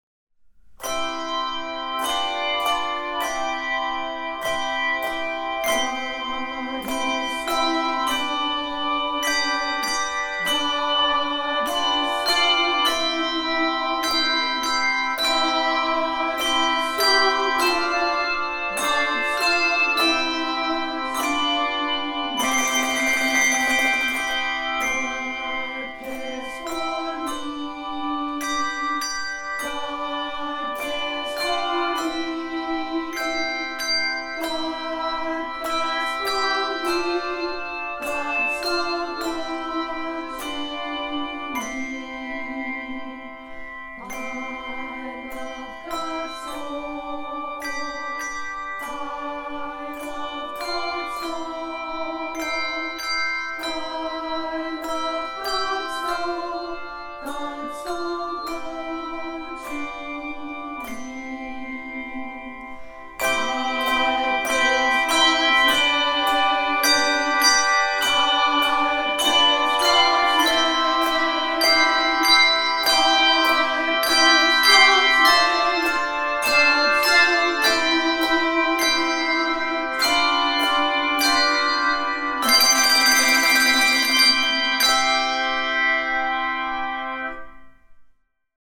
Voicing: Uni/2-3Octave